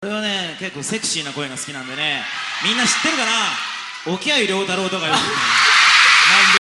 The clip where Midorikawa-san says his name has him with a cheeky grin on his face.